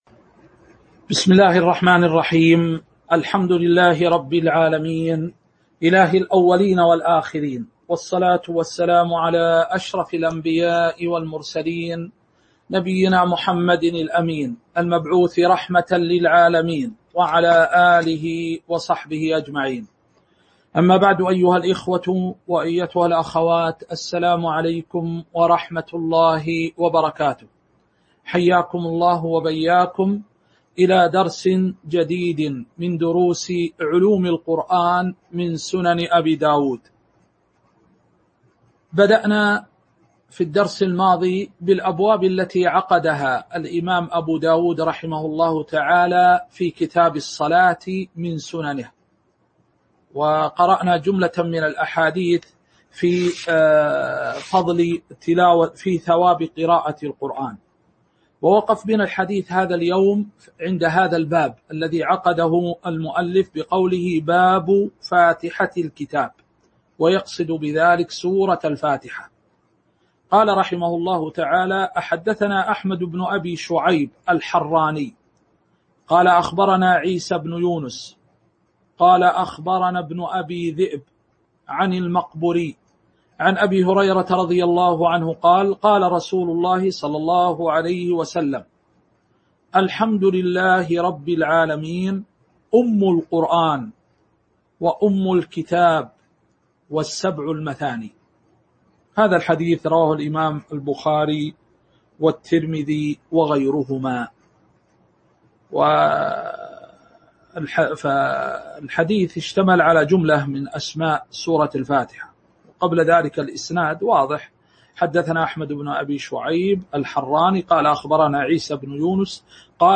تاريخ النشر ٢٥ ذو الحجة ١٤٤٢ هـ المكان: المسجد النبوي الشيخ